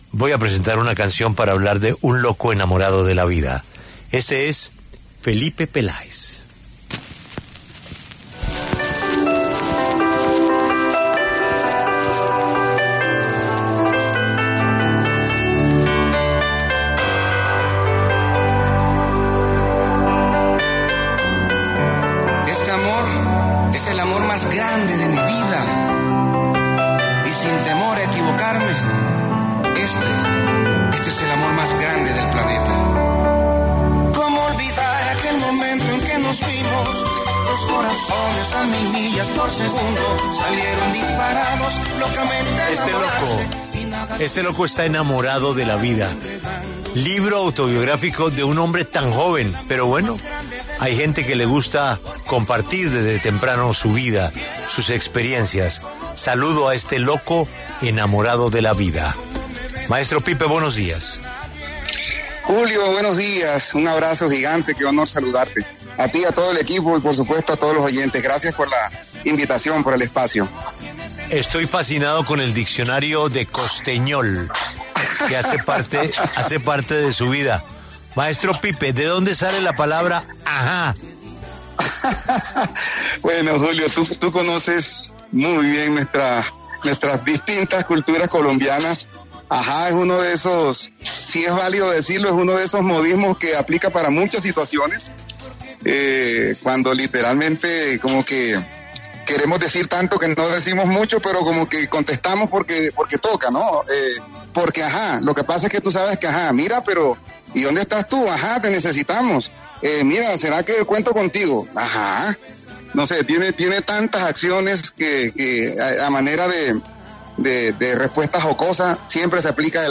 El cantante vallenato Felipe ‘Pipe’ Peláez habló con La W a propósito de su libro ‘Un loco enamorado de la vida’, una autobiografía en la que revela detalles inéditos de su vida.